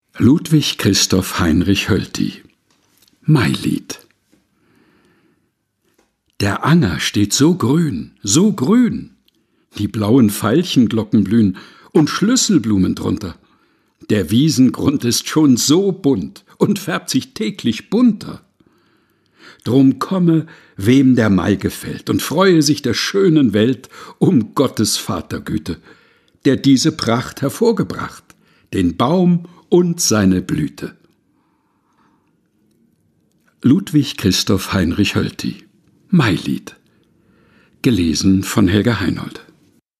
Texte zum Mutmachen und Nachdenken